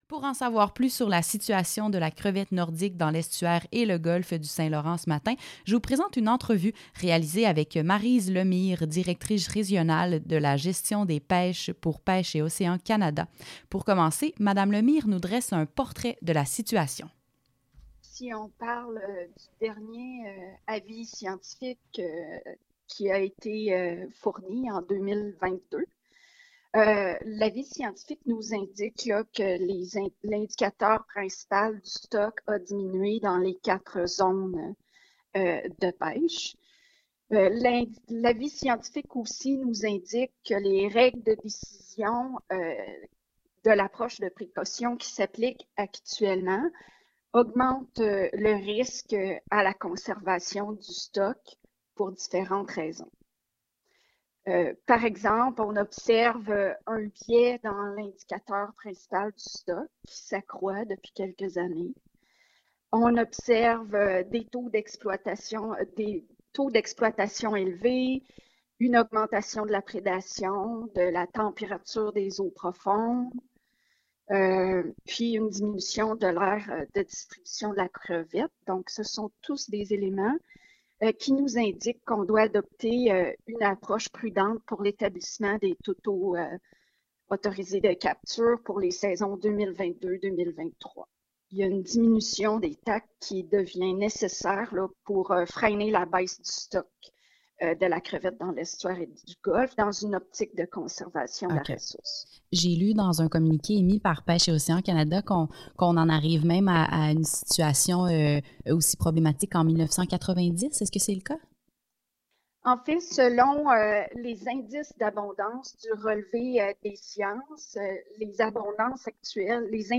Entrevue-crevette-segment-radio.mp3